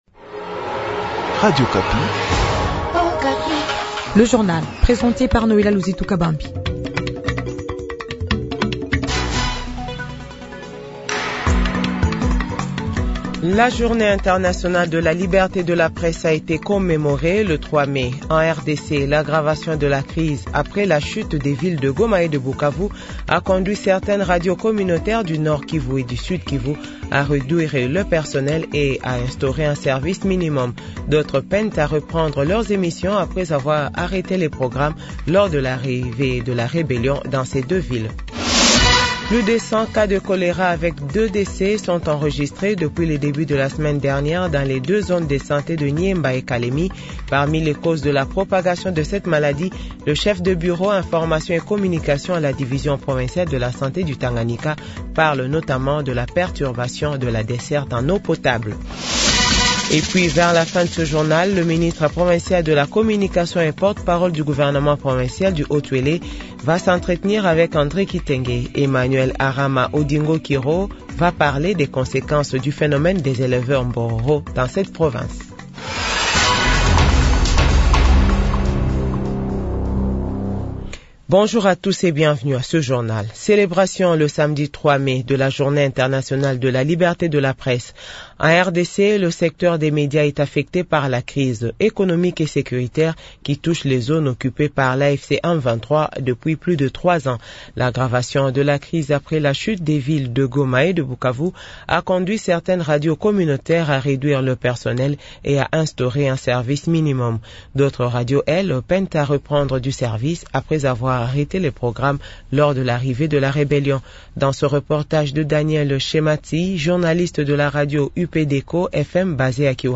Journal 8h00